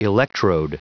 Prononciation du mot electrode en anglais (fichier audio)
Prononciation du mot : electrode